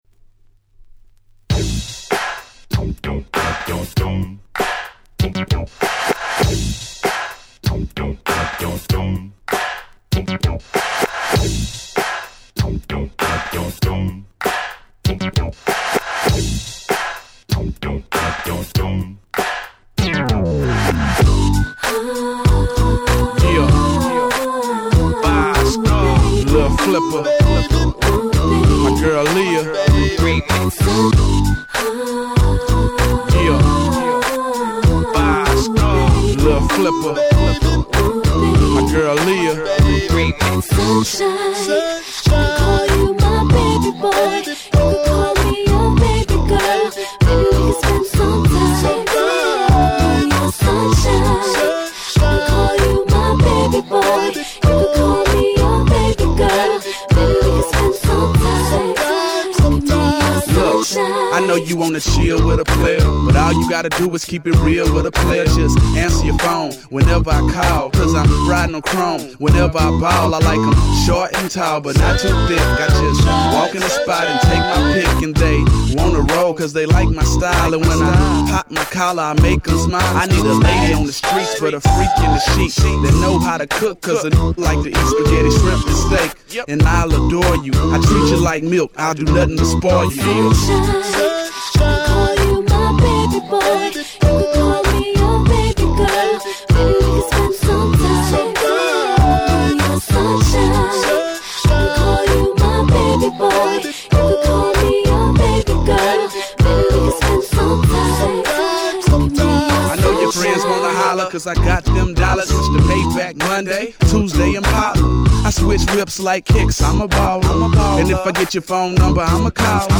West Coast色5割り増し！